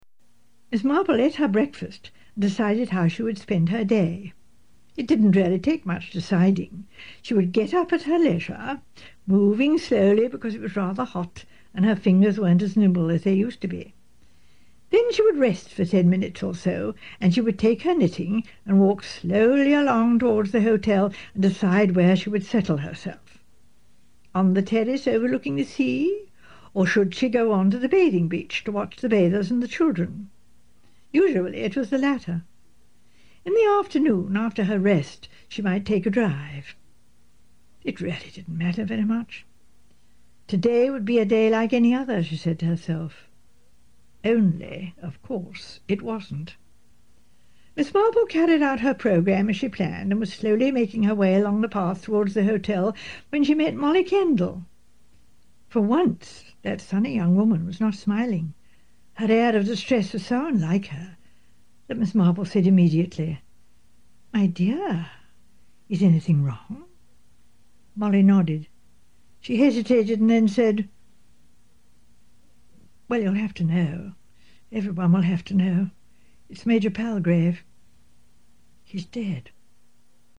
Hickson has portrayed Miss Marple in audio books besides the TV episodes produced by the BBC.
Curious to hear a sound clip of Joan Hickson narrating A Caribbean Mystery from the audio book?